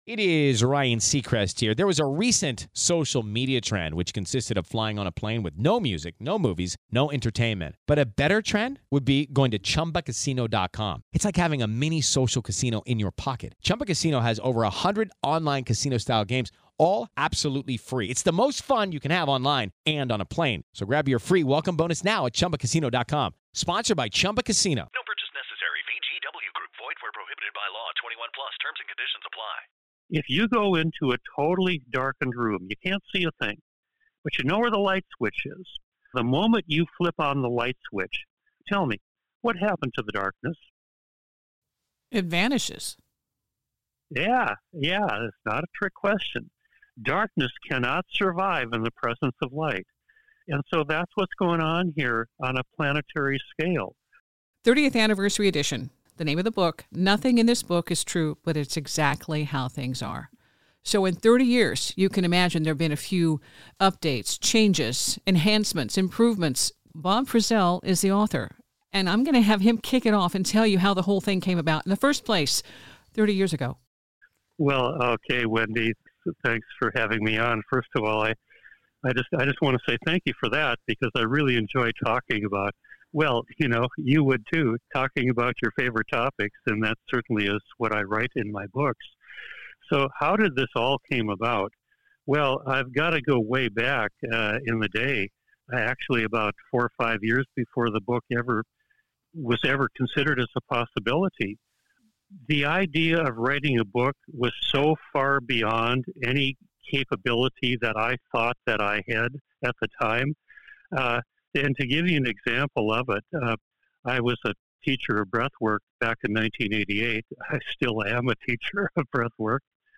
We get into the Breath Alchemy info later in the interview.